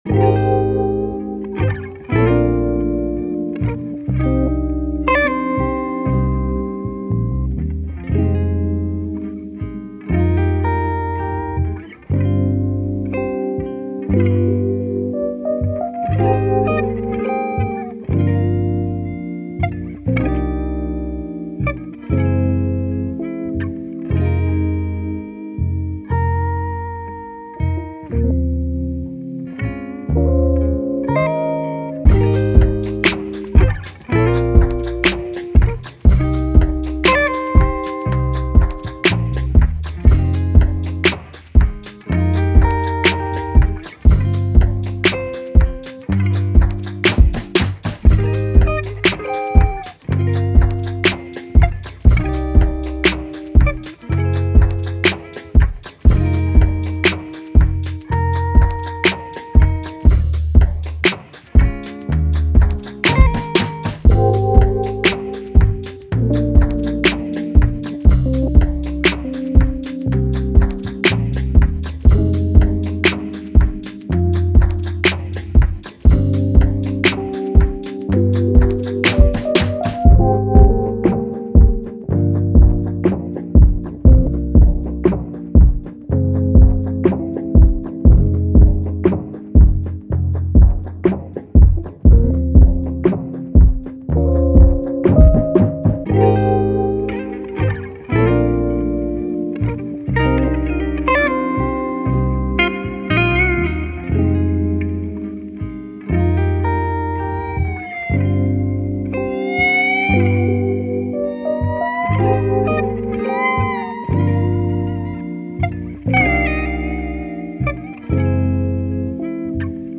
R&B
C Minor